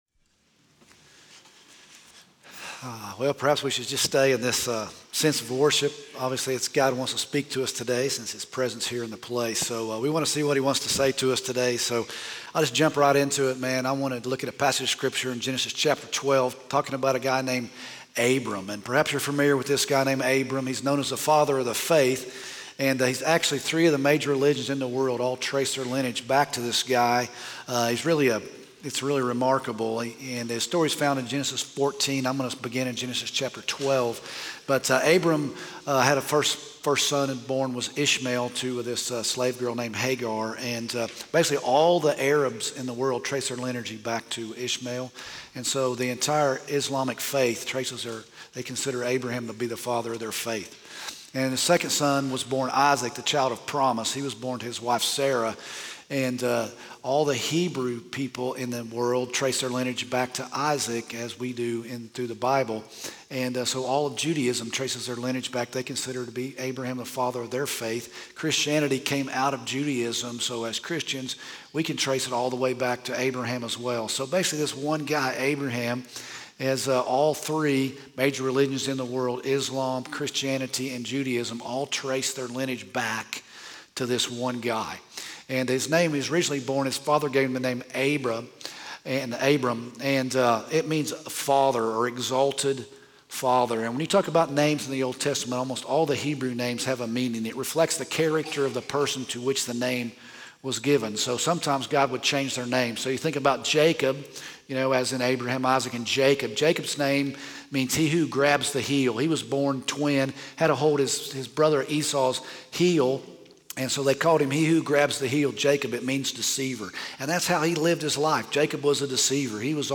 Discussing & Applying the Sermon